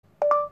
Звук Окей Гугл бип